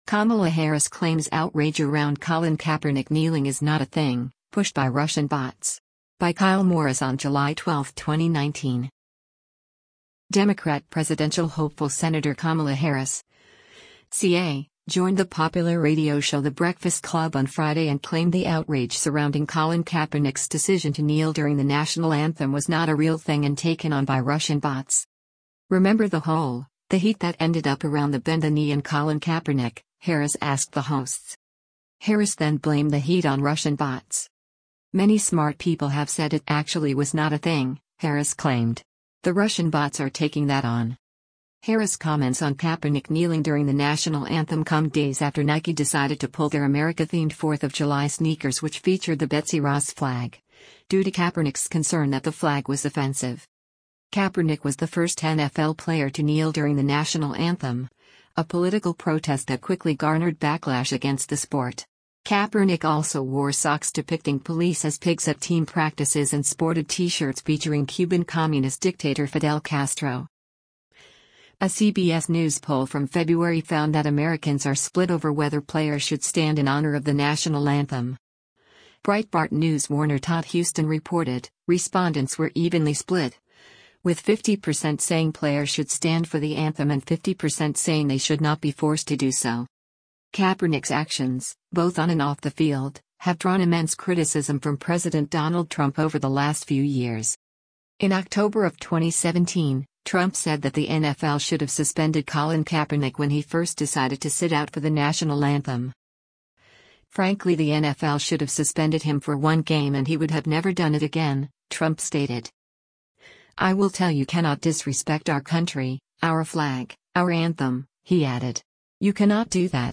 Democrat presidential hopeful Sen. Kamala Harris (CA) joined the popular radio show The Breakfast Club on Friday and claimed the outrage surrounding Colin Kaepernick’s decision to kneel during the national anthem was “not a real thing” and taken on by “Russian bots.”
“Remember the whole, the heat that ended up around the bend the knee and Colin Kaepernick,” Harris asked the hosts.